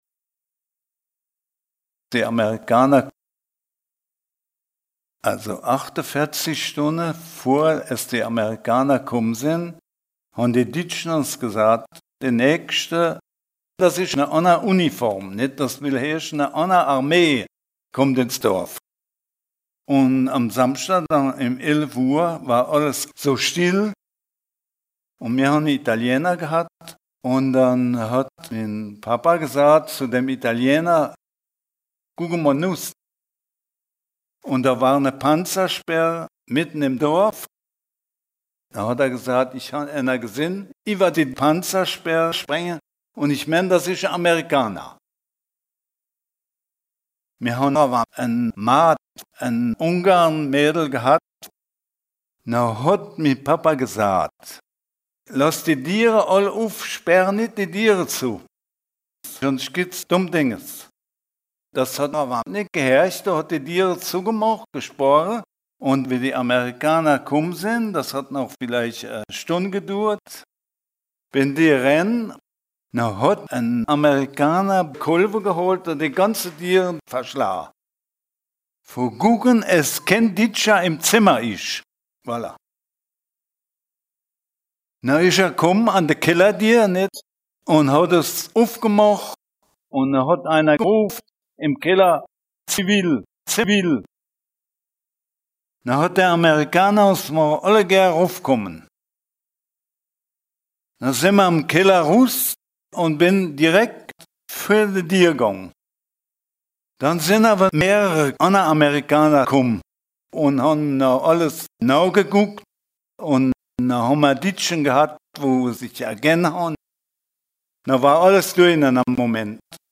Contes et récits en ditsch enregistrés dans les communes de Racrange, Vallerange, Bérig-Vintrange, Harprich, Eincheville, Viller-Béning, Viller, Viller-Boustroff et de Boustroff.